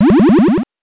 powerup_34.wav